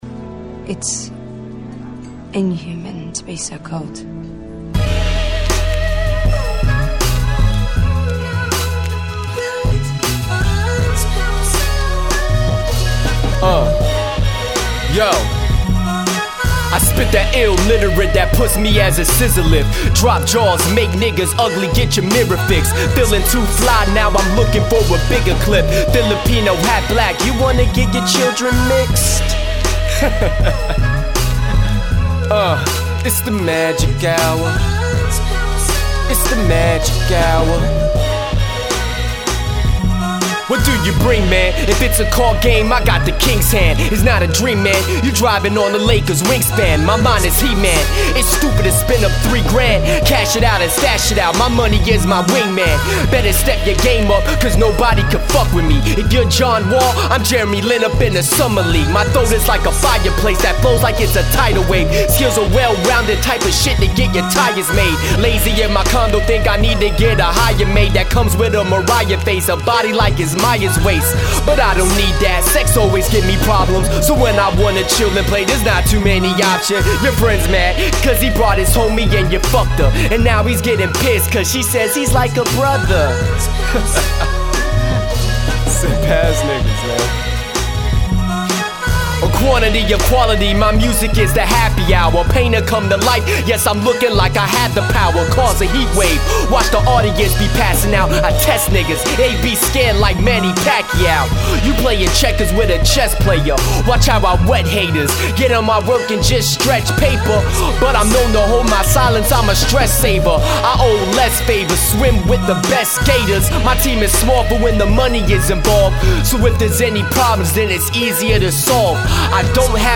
over the new, yet familiar instrumental